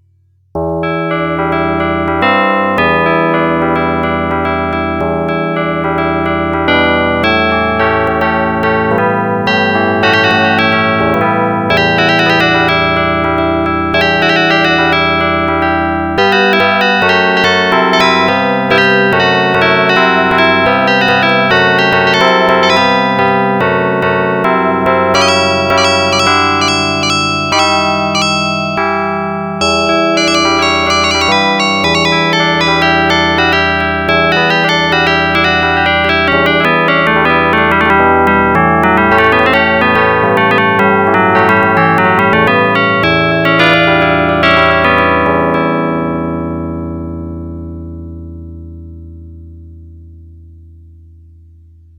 monophonic,
Mono.ogg